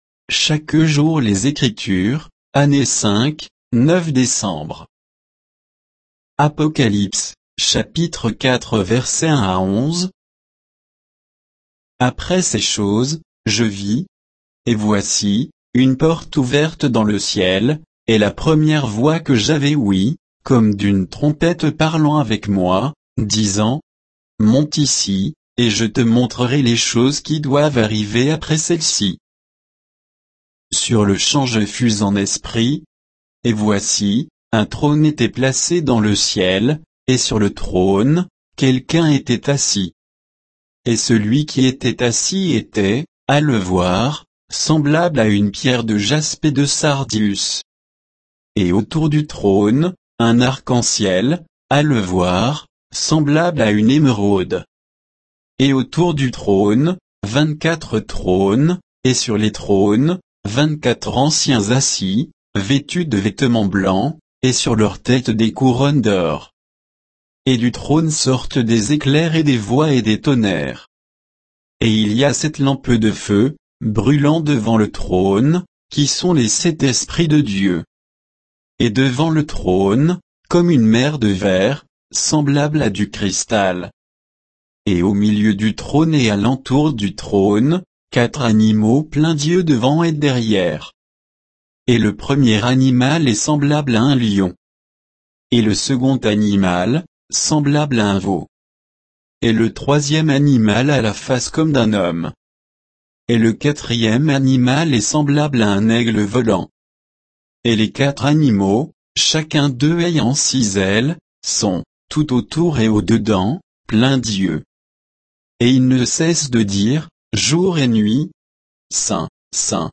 Méditation quoditienne de Chaque jour les Écritures sur Apocalypse 4, 1 à 11